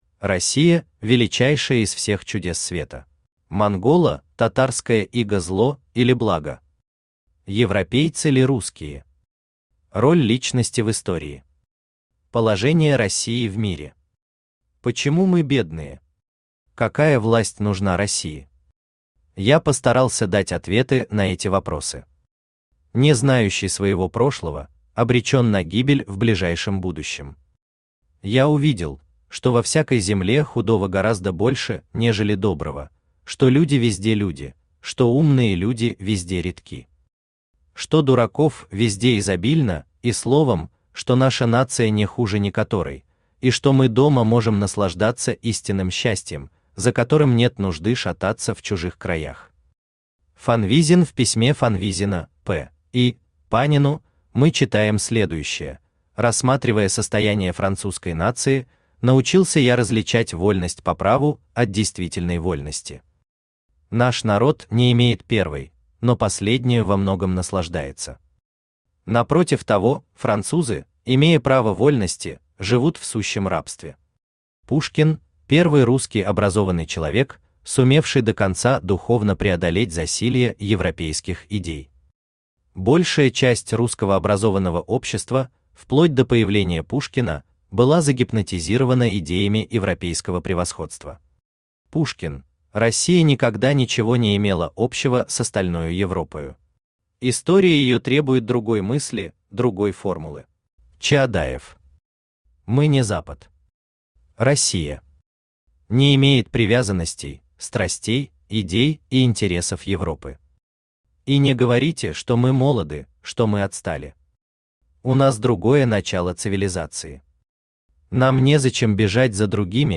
Аудиокнига Россия – величайшее из всех чудес света | Библиотека аудиокниг
Aудиокнига Россия – величайшее из всех чудес света Автор Николай Николаевич Самойлов Читает аудиокнигу Авточтец ЛитРес.